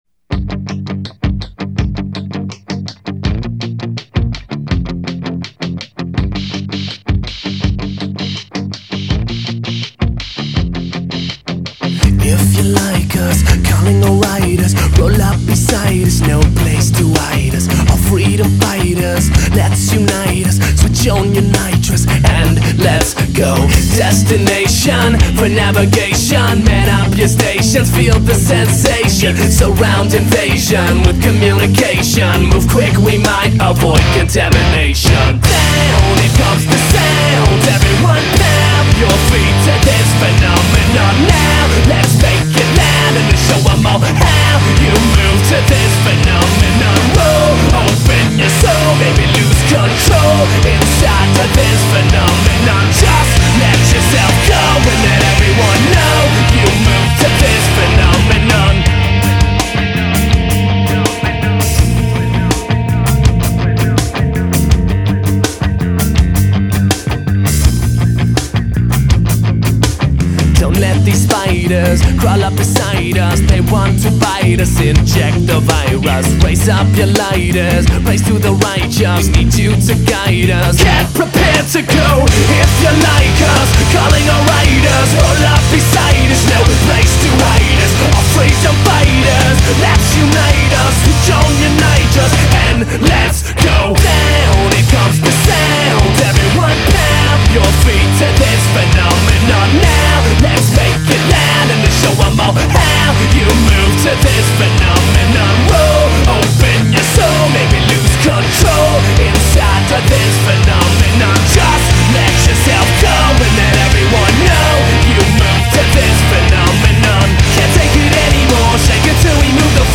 Данная песня находится в музыкальном жанре Trance.